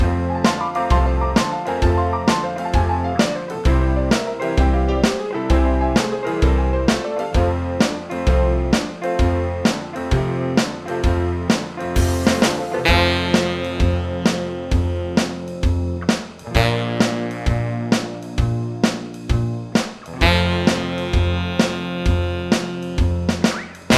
For Duet With Backing Vocals Jazz / Swing 3:15 Buy £1.50